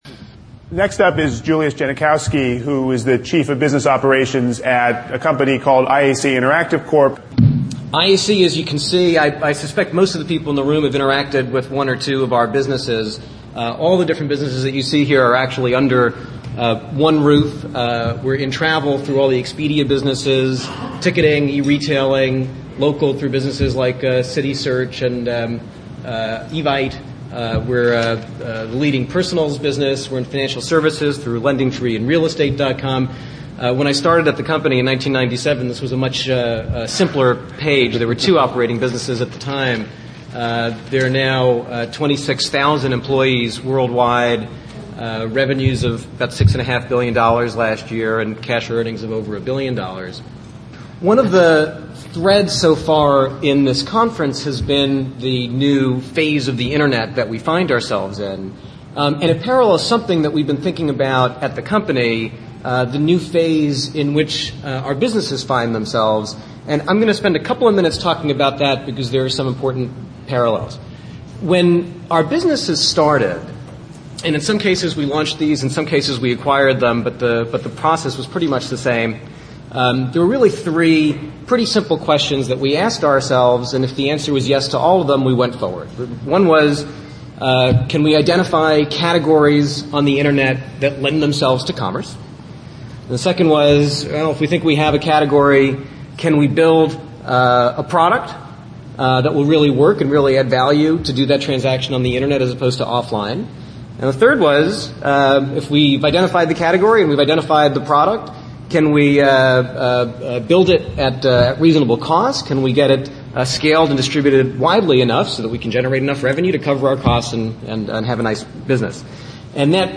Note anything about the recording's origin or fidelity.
This is an excerpt from the IT Converstations recording of the panel on Distributed Business at Supernova 2005 .